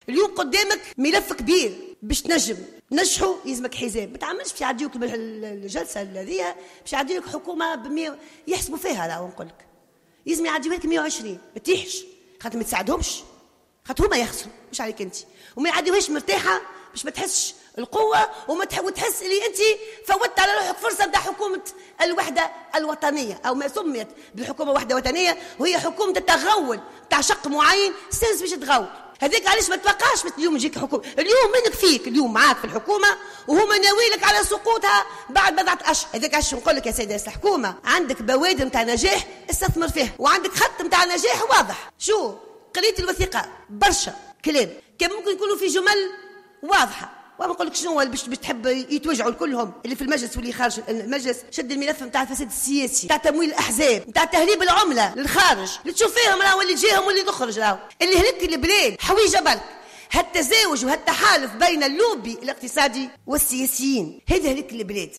تتواصل مداخلات النواب في جلسة منح الثقة لحكومة الياس الفخفاخ المقترحة حتى اللحظة تحت قبة البرلمان و التي انطلقت منذ صباح اليوم الأربعاء.